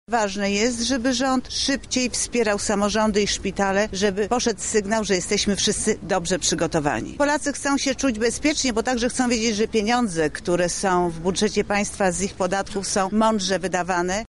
Małgorzata Kidawa-Błońska spotkała się z mieszkańcami Lublina
MKB  – mówi Małgorzata Kidawa – Błońska.